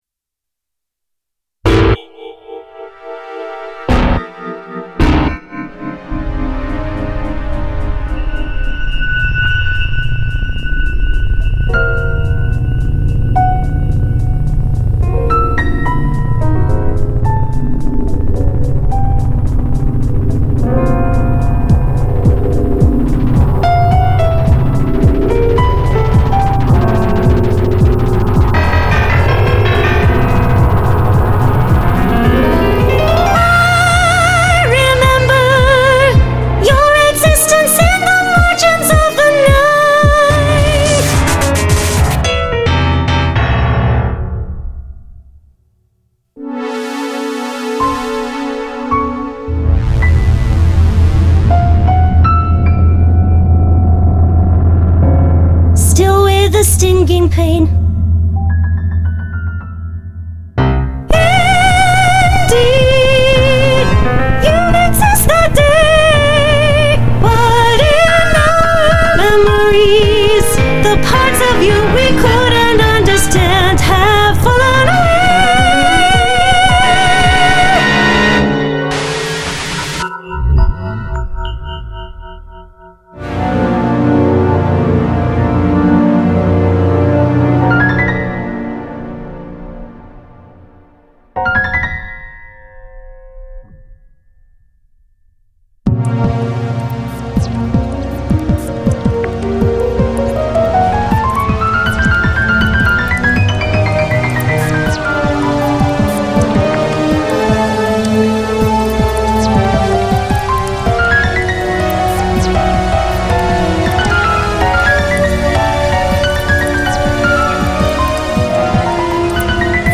Everything Falls Away - for Soprano, Piano and Electronics - signes musique/composition/mixtures
この周縁性を今やろうとするなら、やはり今時のビートに乗ったポピュラーソングがベースになるだろう。
デモ音源に用いたソプラノの声はSynthesizer Vであるが、これが（VOCALOIDのピアプロスタジオと同様）変拍子・テンポチェンジに対応していないため、高度なことはなかなかできなかった。